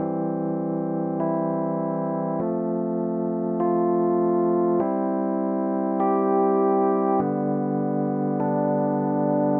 悲伤
Tag: 100 bpm Rap Loops Piano Loops 1.62 MB wav Key : Unknown